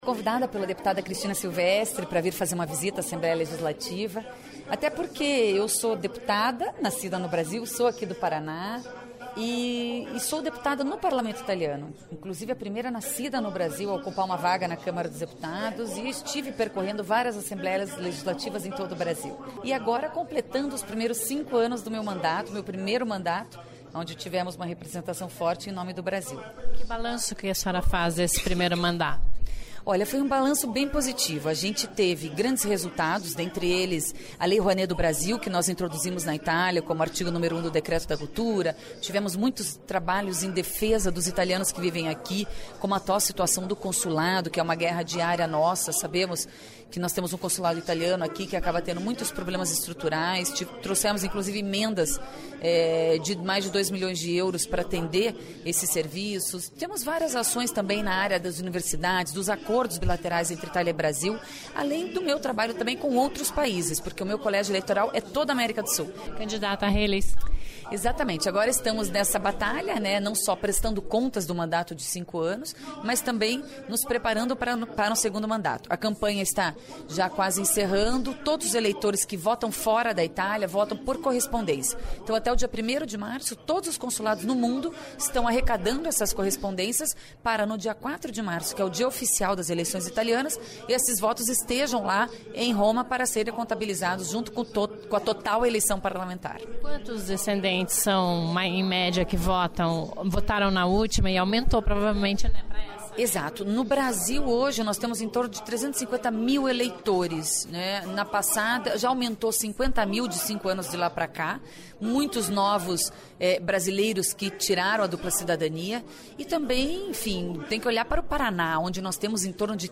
Confira a entrevista com renata Bueno, primeira brasileira a exercer um mandato como deputada no Parlamento Italiano e saiba quais as principais leis que ela conseguiu aporvar e as bandeiras da parlamentar, que luta pela reeleição.